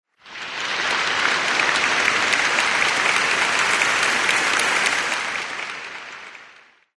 Applaudissement5-1.mp3 (82.86 Ko)